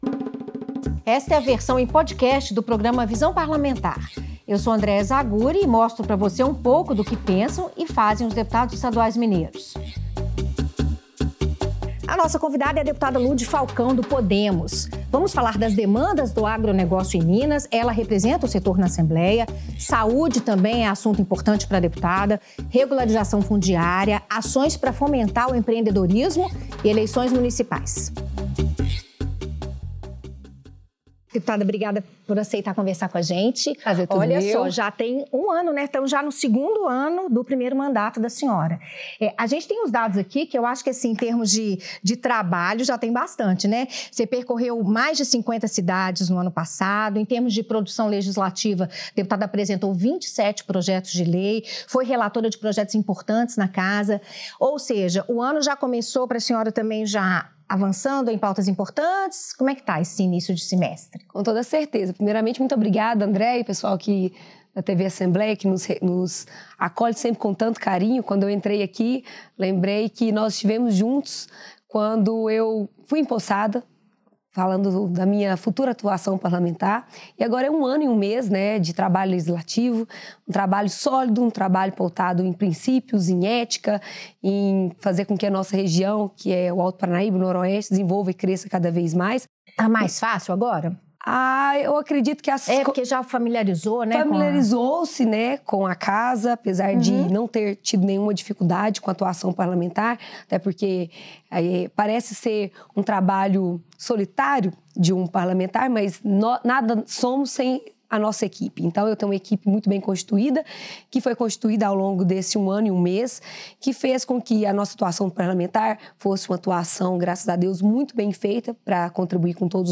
Em seu primeiro mandato na Assembleia, a deputada defende um amplo processo de regularização dos imóveis urbanos de Minas Gerais. Lud Falcão também luta pelo fortalecimento do agronegócio no estado e pede mais investimentos na geração de energia elétrica. A deputada fala ainda sobre a Festa Nacional do Milho (Fenamilho), em Patos de Minas.